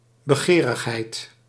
Ääntäminen
Synonyymit graagte gulzigheid begeerte Ääntäminen Tuntematon aksentti: IPA: /bəˈɣeːrəˌɣɛi̯t/ Haettu sana löytyi näillä lähdekielillä: hollanti Käännös Ääninäyte Substantiivit 1. avidité {f} France Luokat Substantiivit